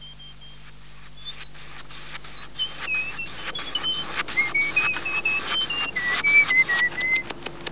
sharpen.mp3